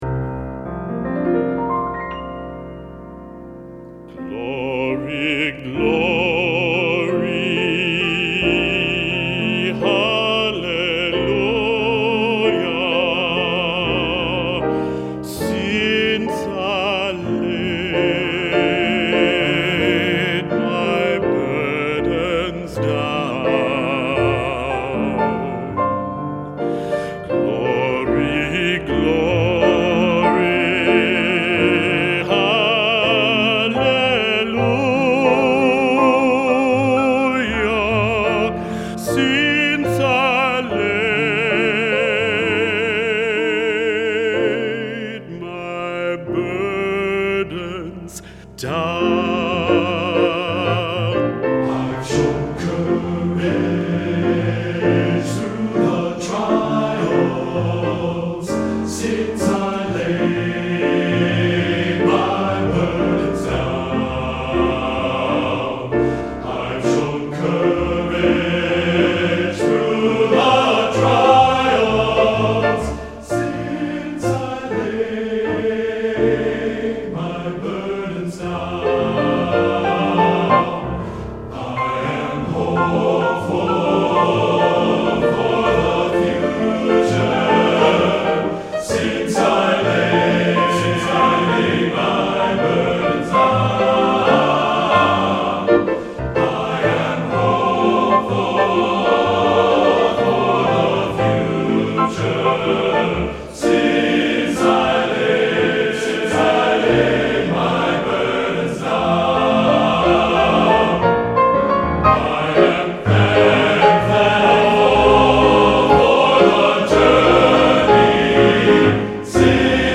Voicing: TTBB